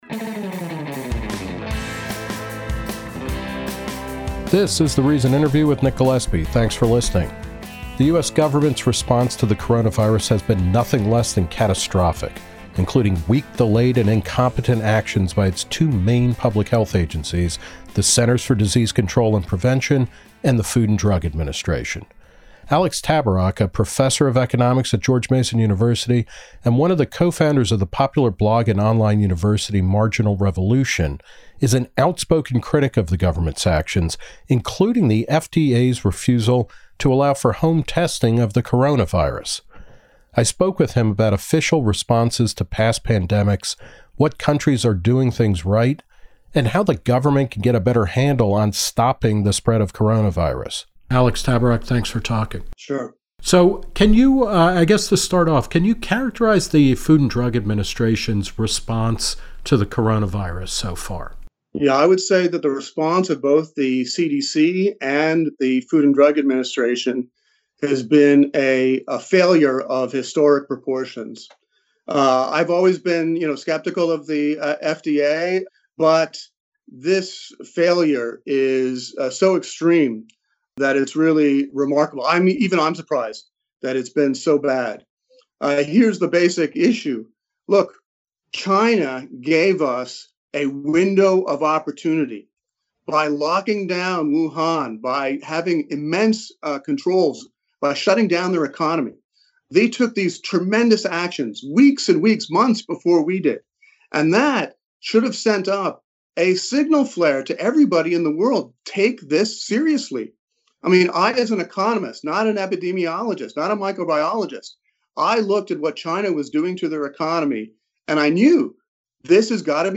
Reason spoke with him about official responses to past pandemics, which countries are doing things right, and how the government can get a better handle on stopping the spread of this novel coronavirus.